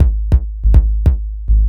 Kick 142-BPM.wav